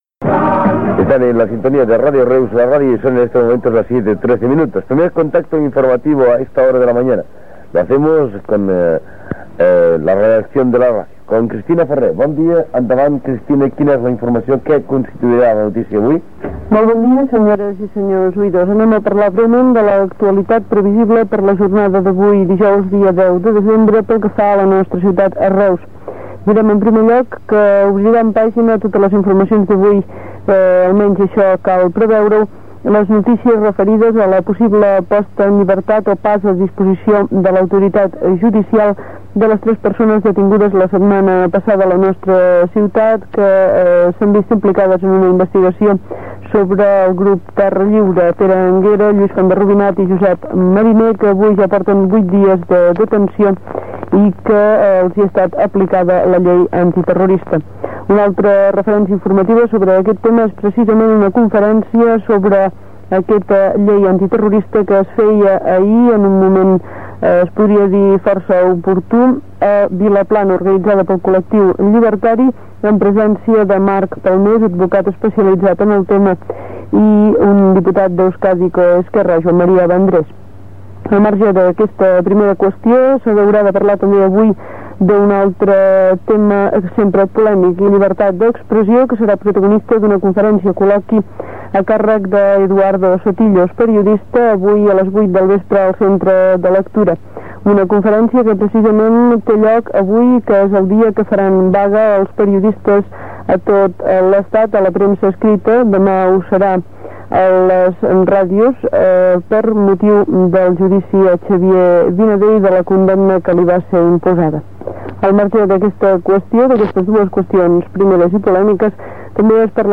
Butlletí de notícies: detinguts de Terra Lliure i conferència sobre la llibertat d'expressió.
Informatiu